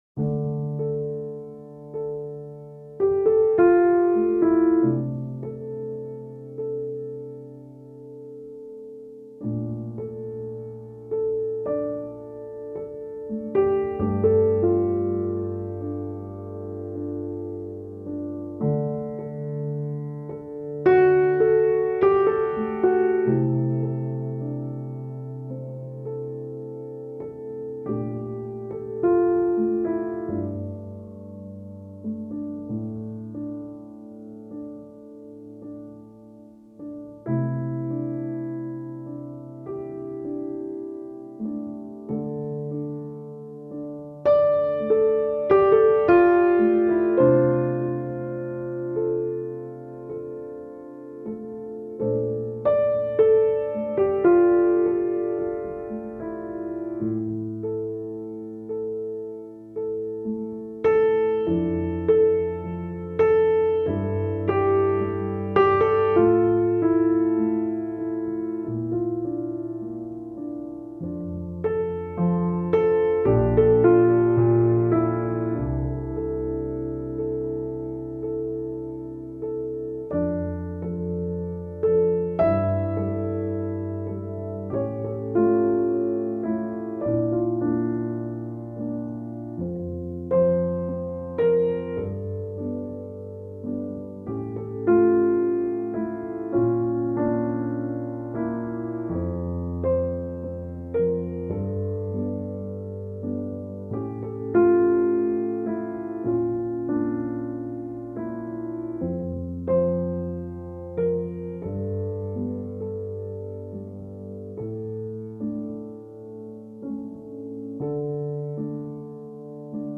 Naked piano melody creating a lonesome and heartbroken feel.